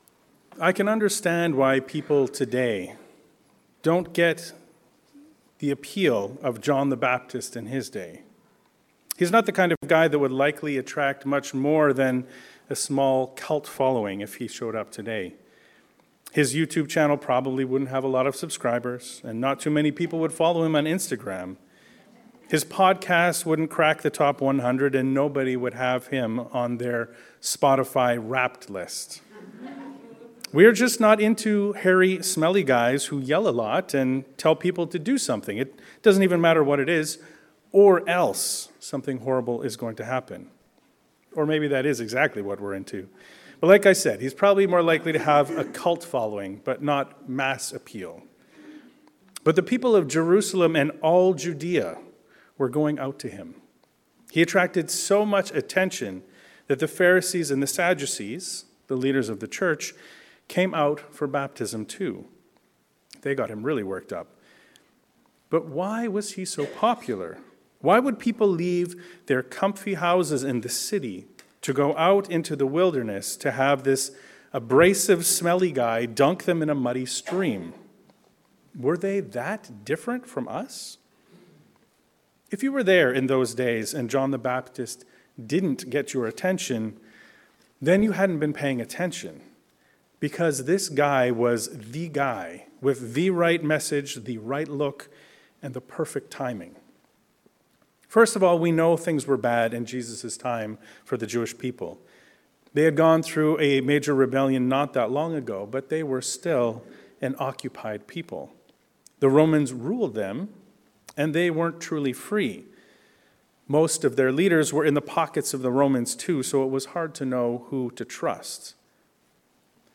A shoot from the root of Jesse. A sermon on Isaiah 11.1-10 and Matthew 3.1-12